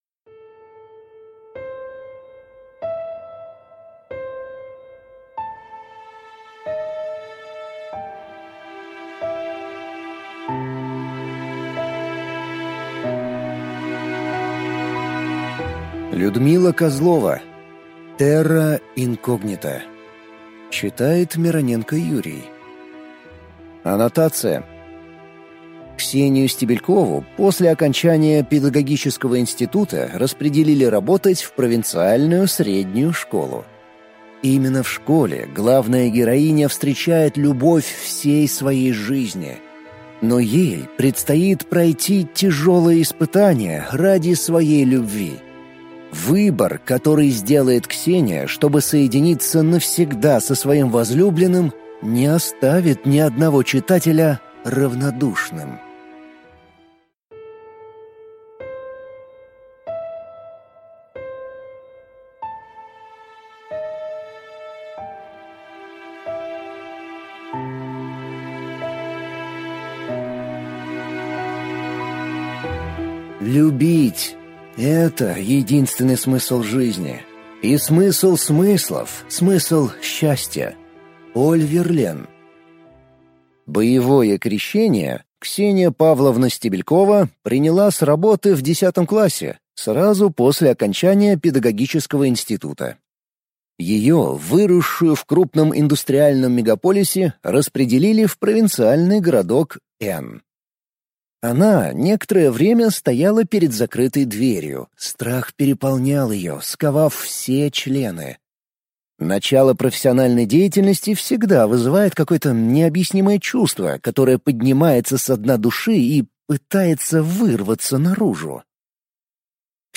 Aудиокнига Terra incognita